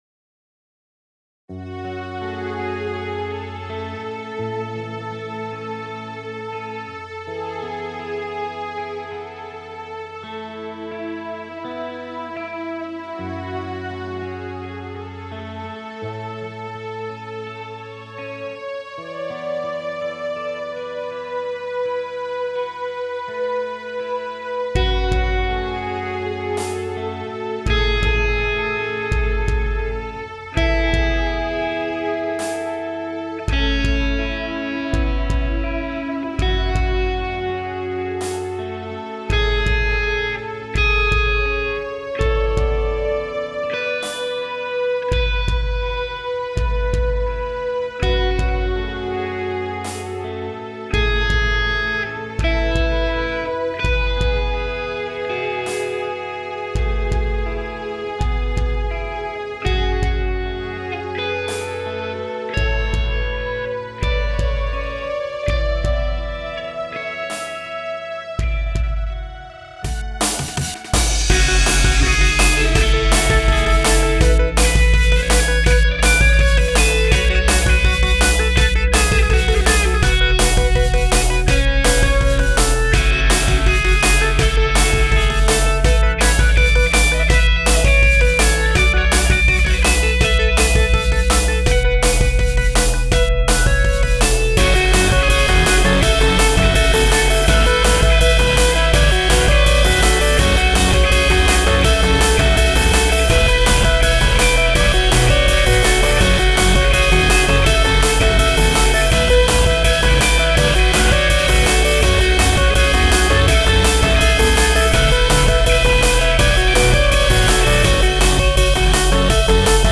2) "Deepwater Darkness" - I really like the slow introduction; the guitar pairs really nicely with the accompaniment. I also enjoy the arc - after it picks up from its somewhat somber pace, it slows back down in the last few seconds and that concludes the piece so well. I do get underwater ocean vibes overall, especially from the beginning and end.